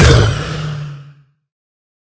hurt2.ogg